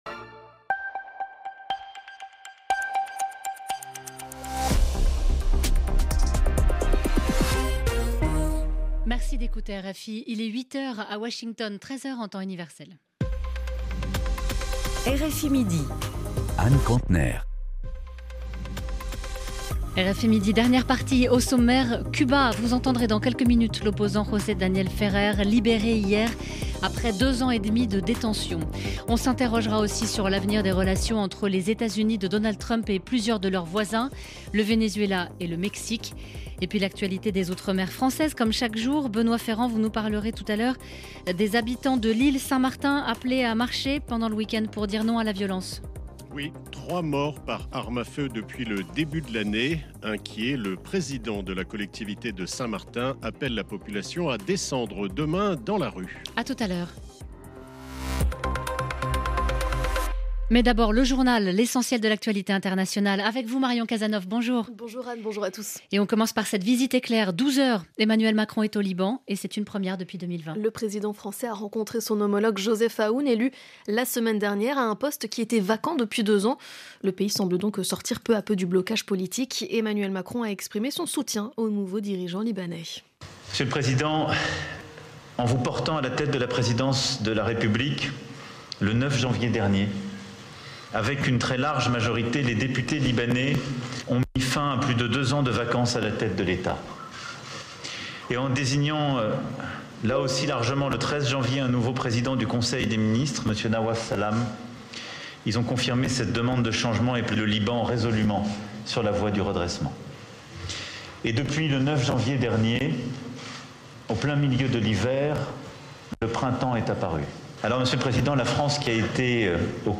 C’est pour cet auditoire que, chaque jour, RFI consacre un reportage, ou une interview, spécifiquement consacré à Haïti.